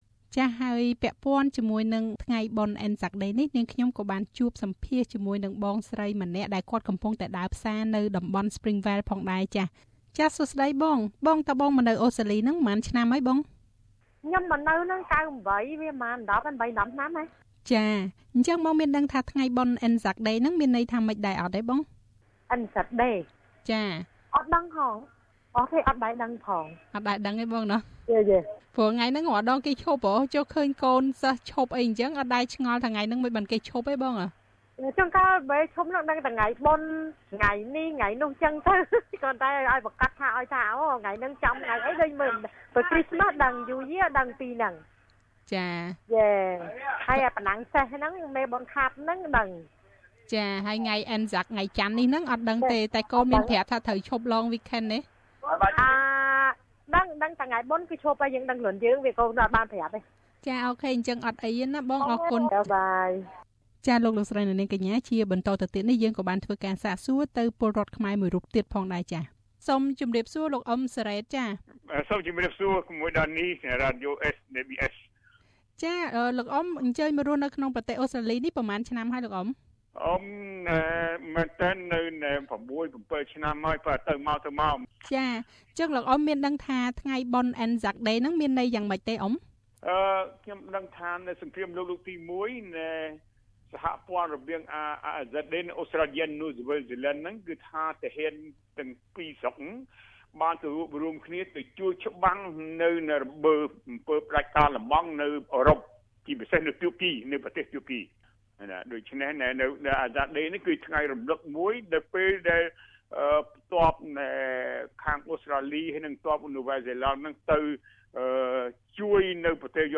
មិនមែនអ្នកគ្រប់គ្នាសុទ្ធតែមានដឹងពីអត្ថន័យនៃទិវា ANZAC នោះទេ។ តើ ANZAC Day មានអត្ថន័យយ៉ាងណាចំពោះសហគមន៍ប្រវេសន៍ដែលកំពុងតែរស់នៅក្នុងប្រទេសអូស្រ្តាលី ដូចជាខ្មែរយើងជាដើមនោះ? សូមស្តាប់នូវបទសម្ភាសន៍ដូចតទៅ៖